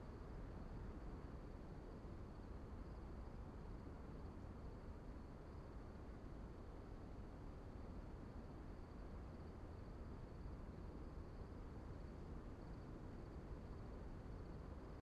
sfx_amb_combat_thegreatcity.ogg